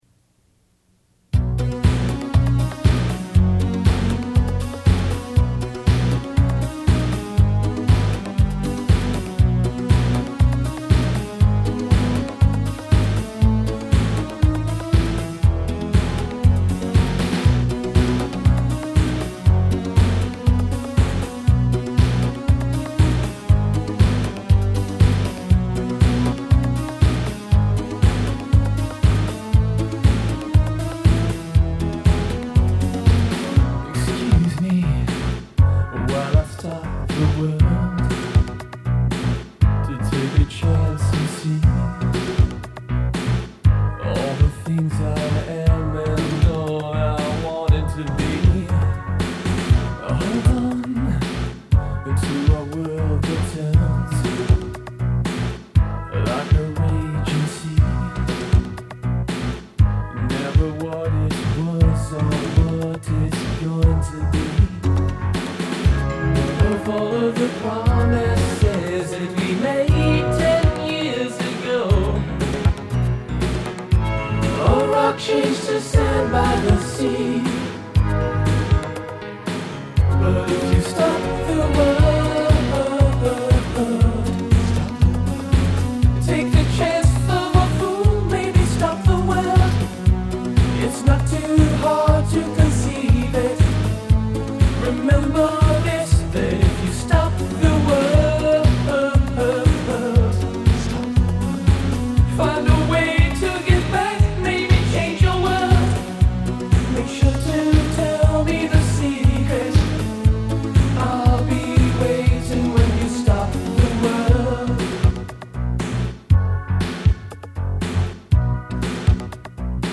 in the Cleveland Institute of Music Audio room